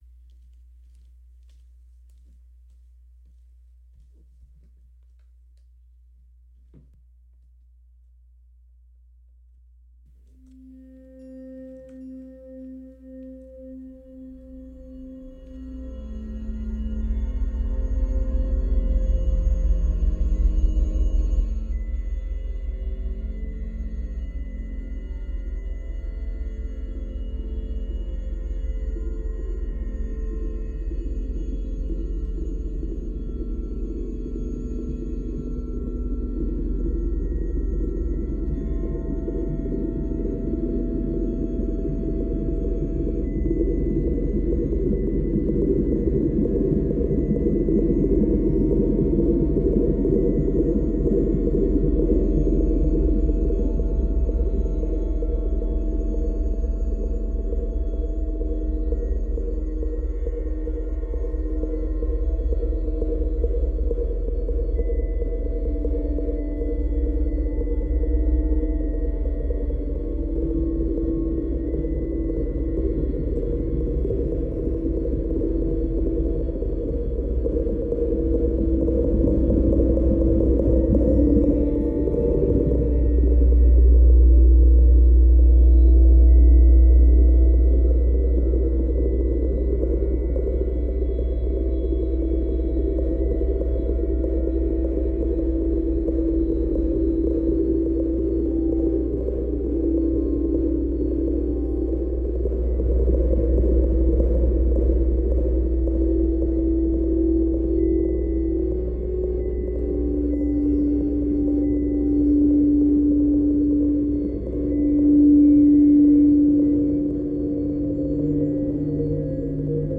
It consists of a performance in which I interact with a video game via an electric guitar where the interaction shapes the unfolding of the audio-visual experience.
Musical gestures cause interactions between various virtual elements which, in turn, produce synthetic sound or process the live guitar.